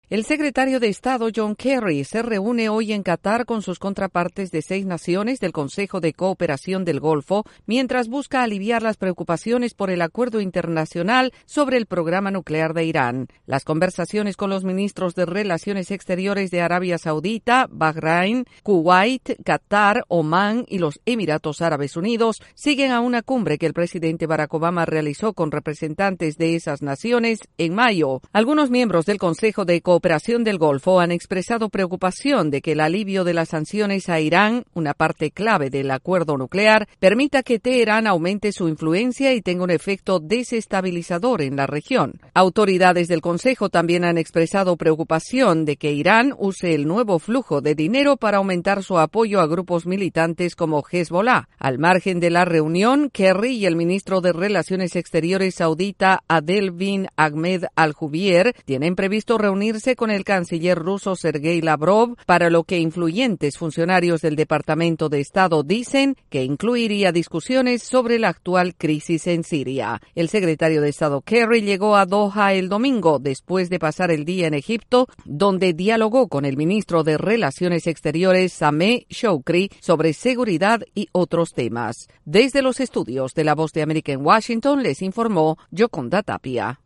El secretario de Estado de Estados Unidos está en Qatar para reunirse con miembros del Consejo de Cooperación del Golfo. Desde la Voz de América en Washington informe